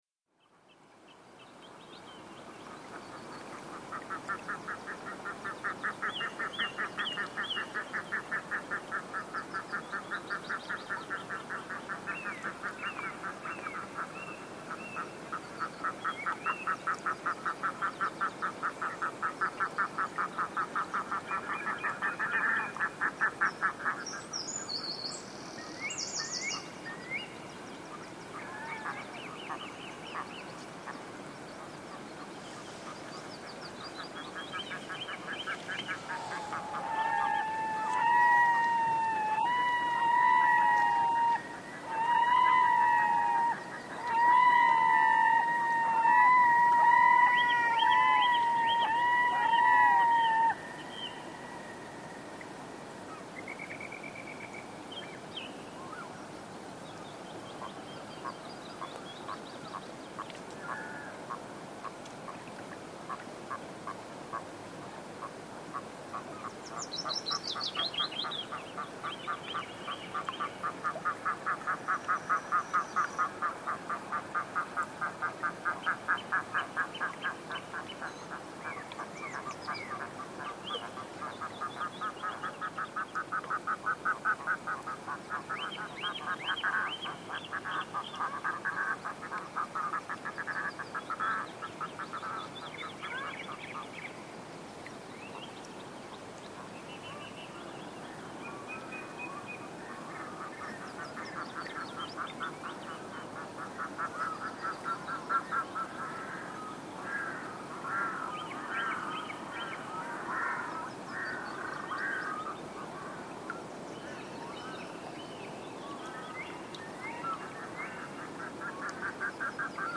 Red-throated diver calls